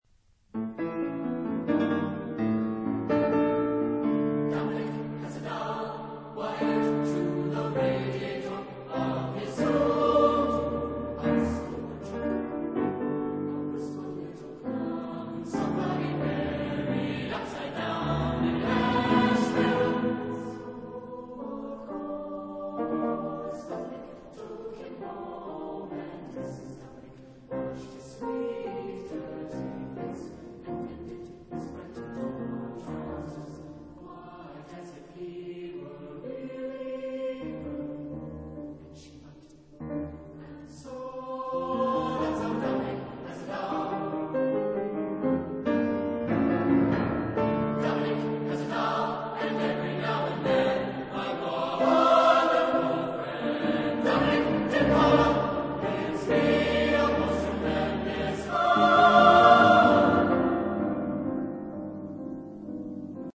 Genre-Stil-Form: Chor ; weltlich
Chorgattung:  (2 Stimmen )
Instrumente: Klavier (1)